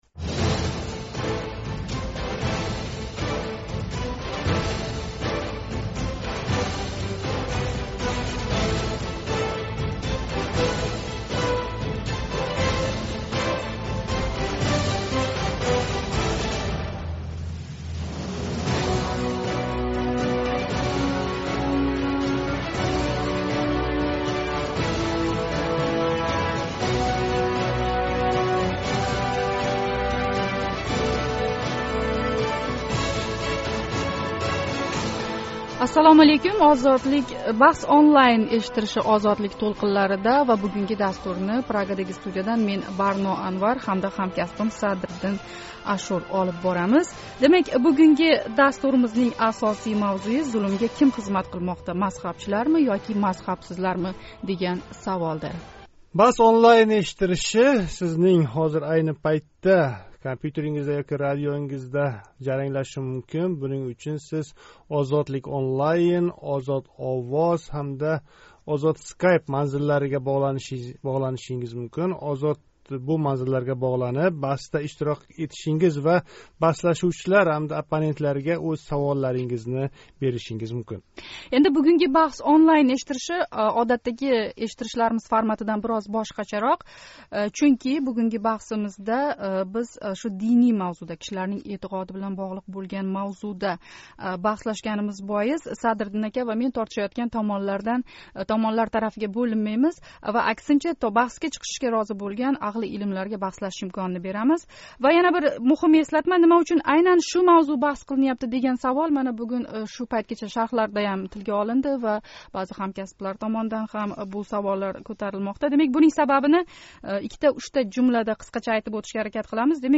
Бунда бир томон мусулмонга мазҳаб керак эмас, у динни бидъатга тўлдирмоқда деса, иккинчи томон мазҳаб керак, аммо фақат бир мазҳабга эргашишга мажбурлаш керак эмас, демоқда. BahsOnline эшиттиришининг навбатдаги сонида шу мавзуда баҳс бўлади.